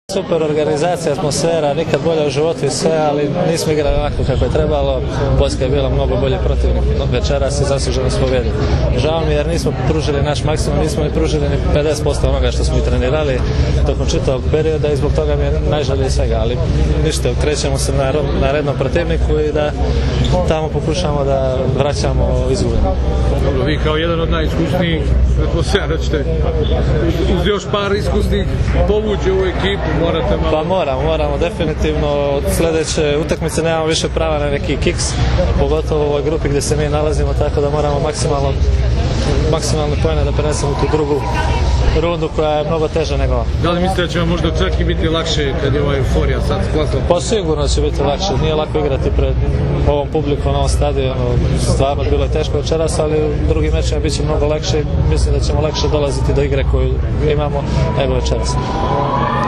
IZJAVA MILOŠA NIKIĆA